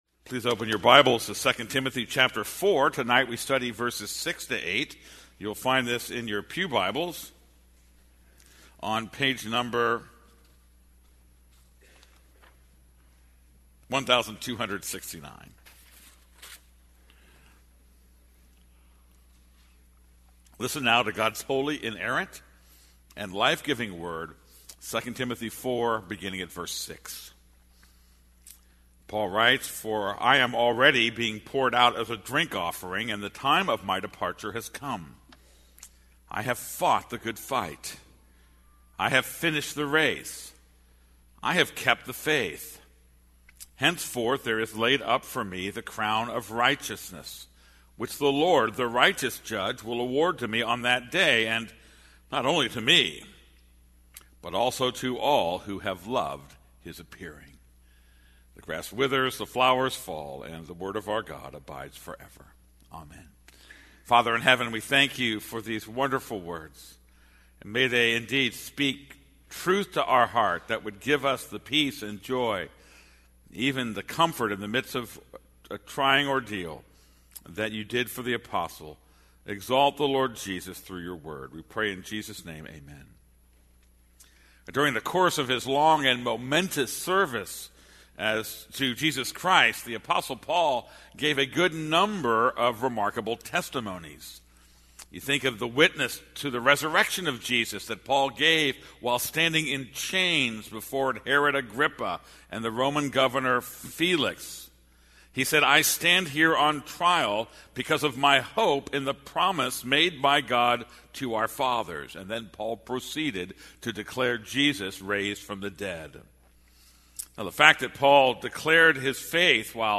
This is a sermon on 2 Timothy 4:6-8.